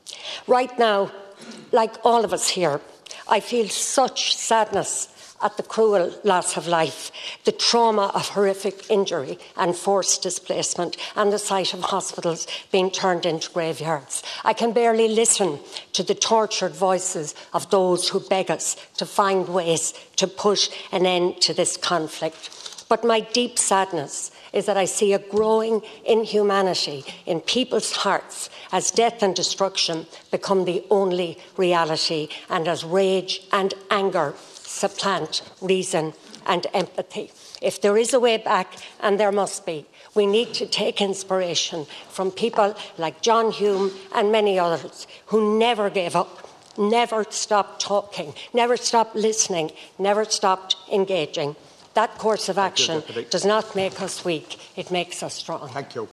South Donegal Deputy Marian Harkin told the Dail that the Government needs to take inspiration from influential figures in Northern Ireland who brought an end to The Troubles by continuing to engage: